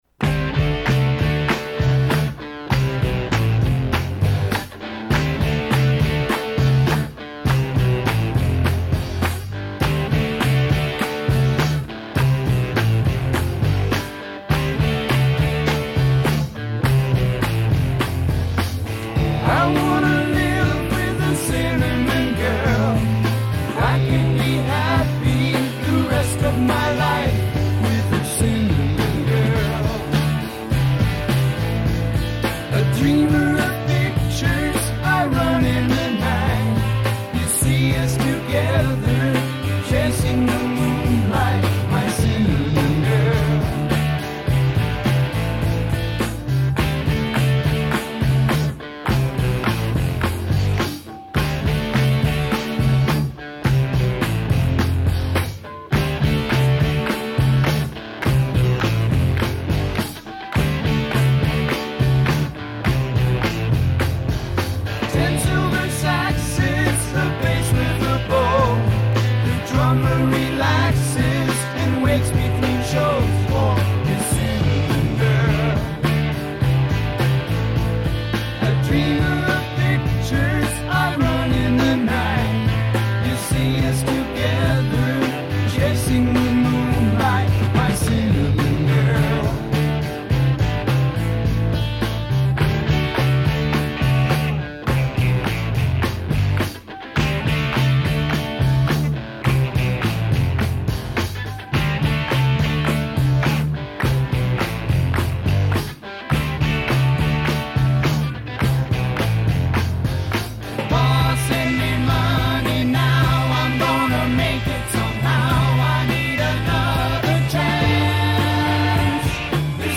Music Monday kicked off today with the BIRSt Playlist Show, which as the name suggests, featured music from our very own playlist!